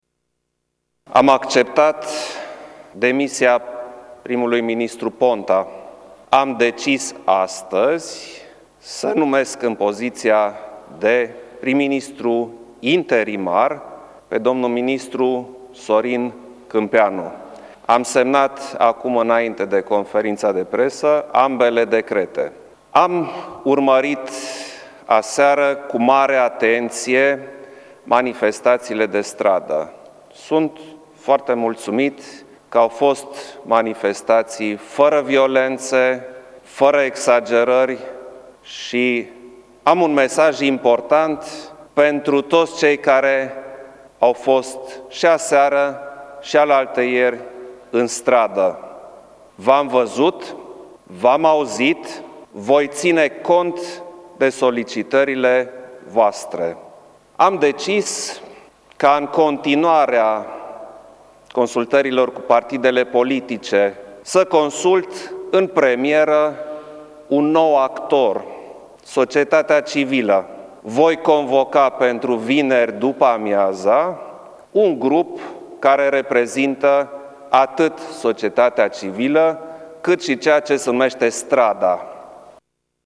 Președintele Klaus Iohannis a declarat, azi, că l-a numit în funcția de prim-ministru interimar pe ministrul Educației.